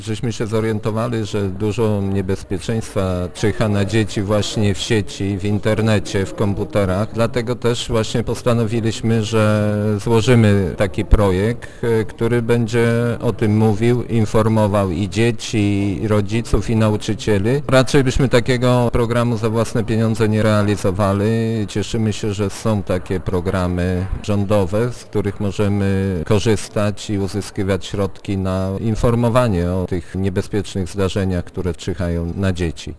Uczestniczący w poniedziałkowej konferencji wójt Jacek Anasiewicz podkreśla, że obecny projekt jest niejako kontynuacją podobnego, który był realizowany przed rokiem: